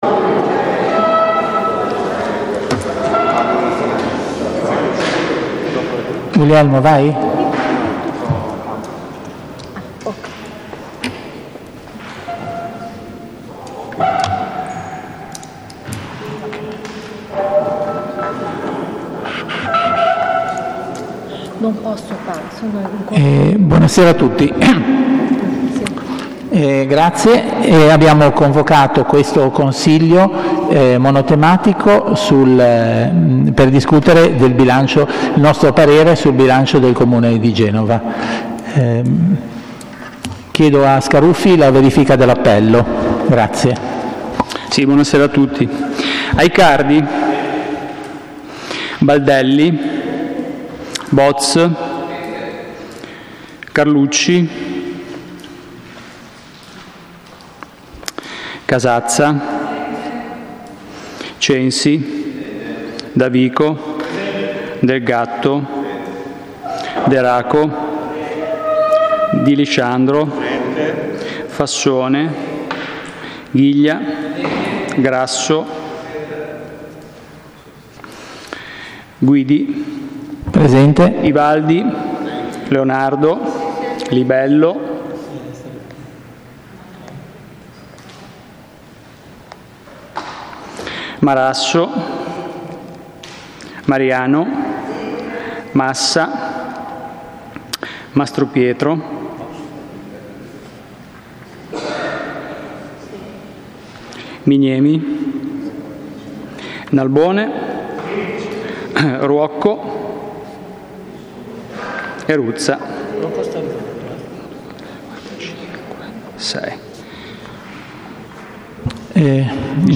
Municipio III - Consiglio